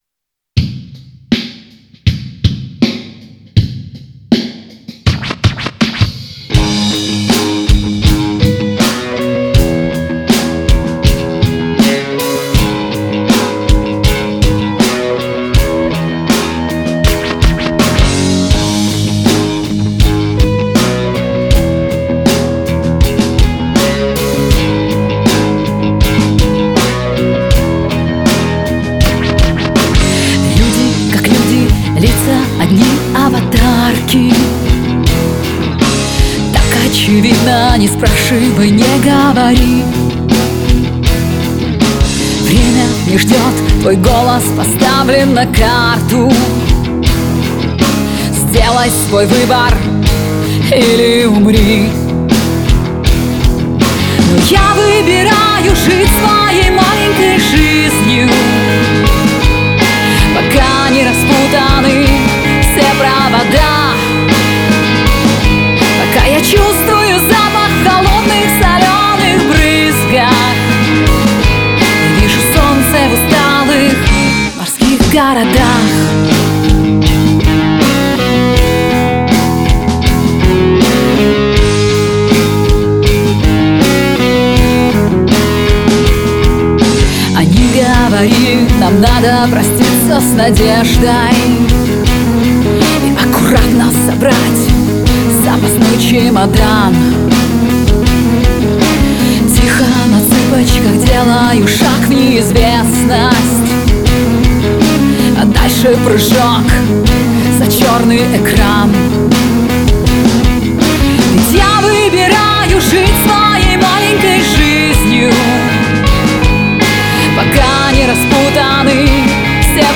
• Жанр: Рок, Русская музыка, Русский рок